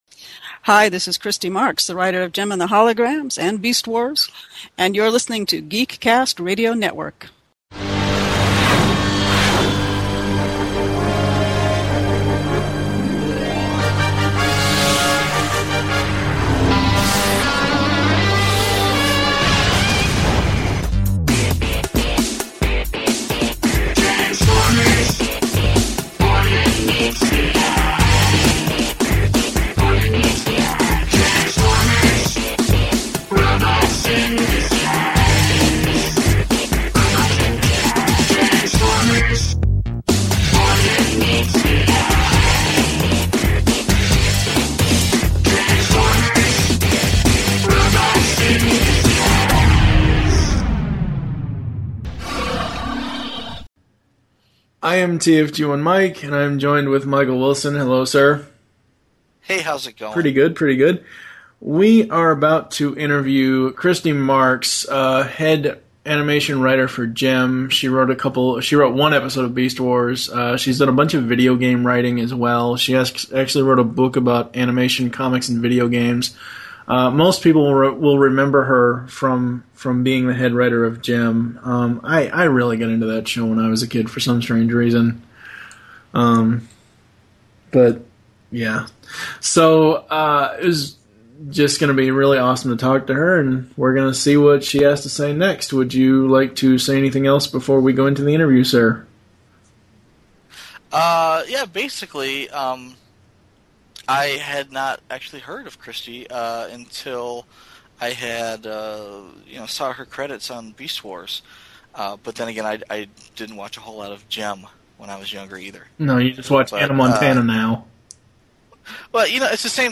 Interviews – Christy Marx